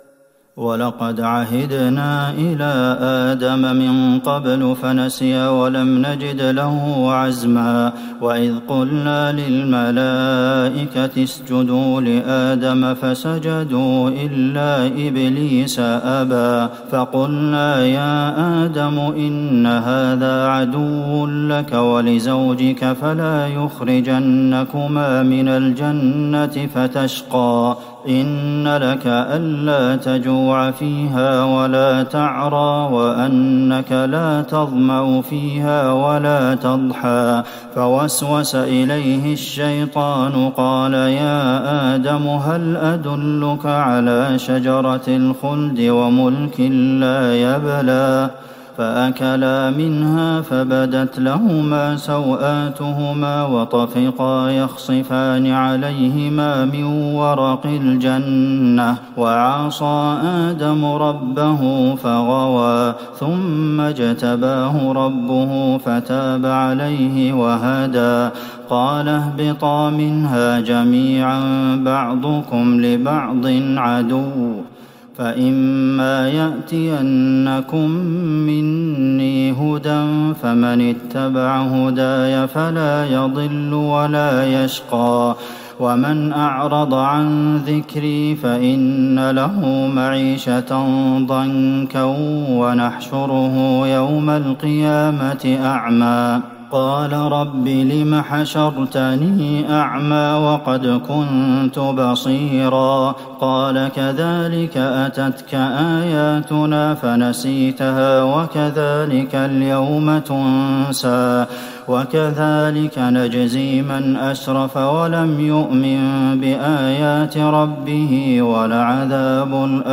تهجد ٢١ رمضان ١٤٤١هـ من سورة طه { ١١٥-١٣٥ } والأنبياء { ١-٥٠ } > تراويح الحرم النبوي عام 1441 🕌 > التراويح - تلاوات الحرمين